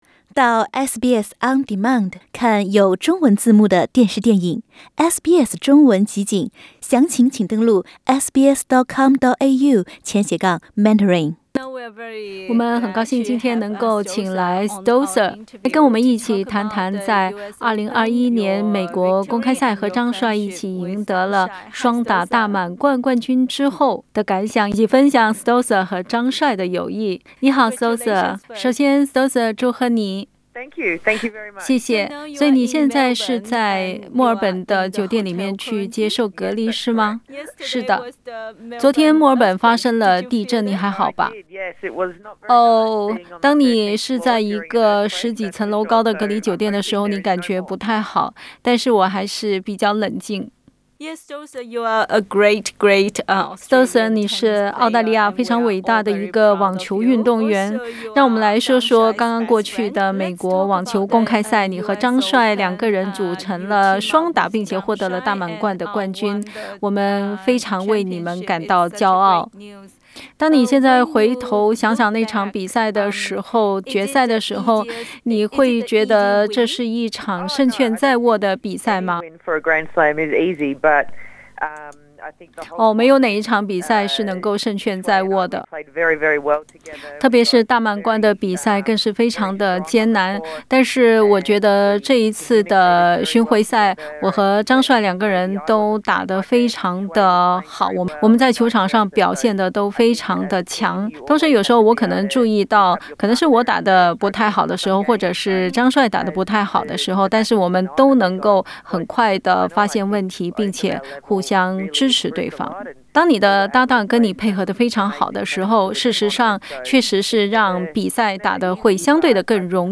斯托瑟/张帅专访：我们的合作与友情（上）